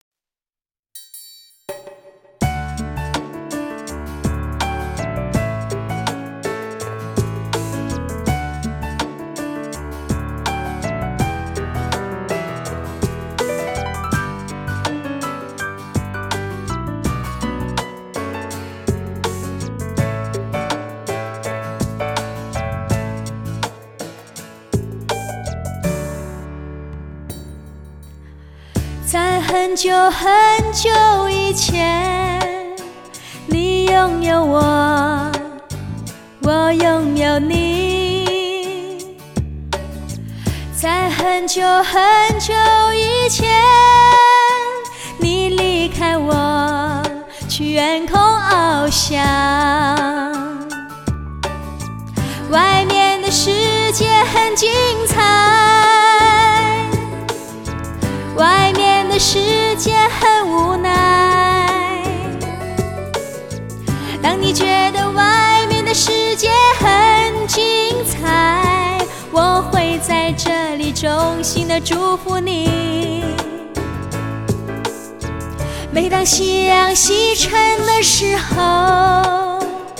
高亢的嗓音，投入的表演，